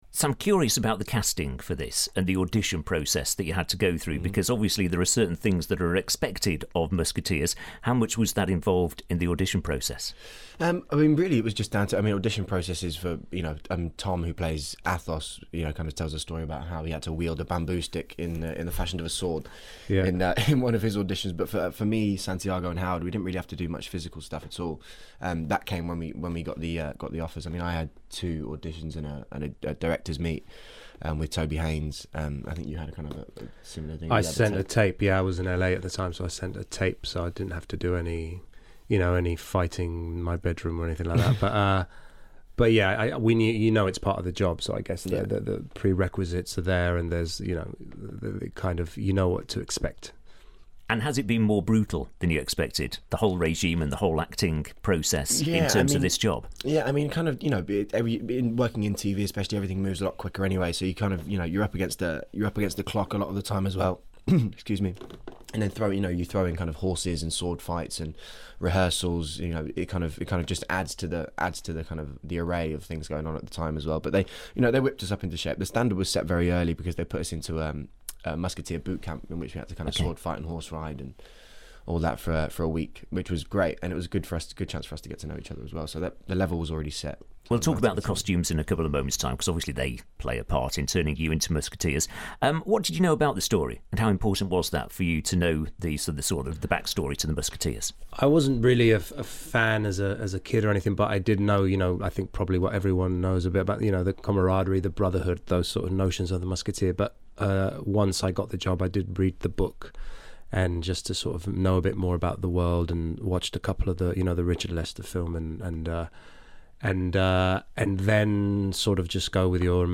Luke Pasqualino (D'Artagnan) and Santiago Cabrera (Aramis) talk about the new BBC 1 Drama series.